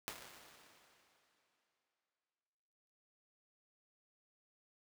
UVR_resources / impulse /VS8F-2 /201-R1_LargeHall.wav
201-R1_LargeHall.wav